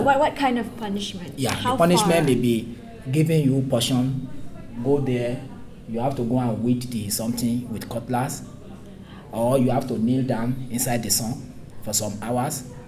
Corpus of Misunderstandings from the Asian Corpus of English
S1 = Bruneian female S3 = Nigerian male
It is possible that the misunderstanding of weed as with occurs because S3 does not always maintain the distinction between long and short vowels; in addition, the final [d] might be heard as [ð] because final TH may be pronounced as [d] in other situations.